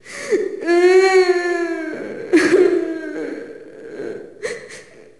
Index of /Downloadserver/sound/zp/zombie/female/